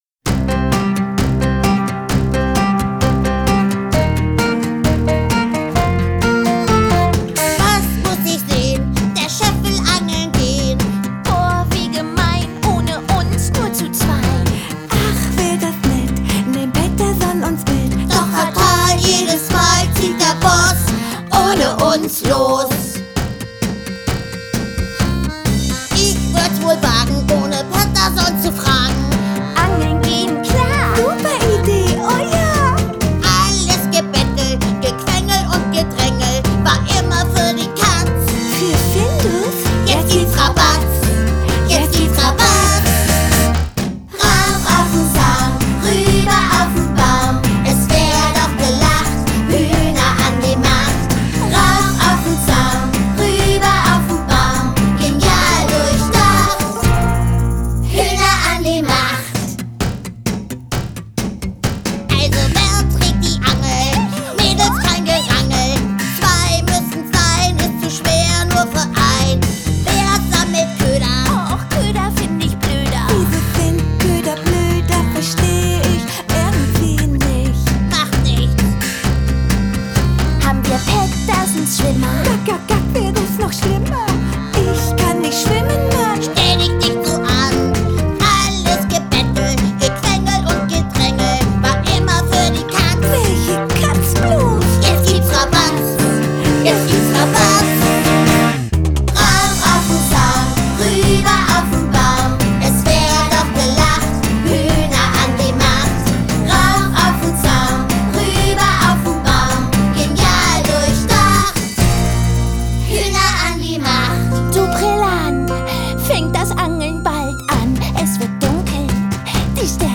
Kinder- / Jugendbuch Gedichte / Lieder